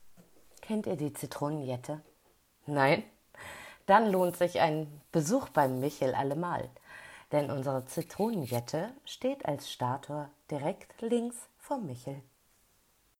• Sprachbeispiele zu Sehenswürdigkeiten in Hamburg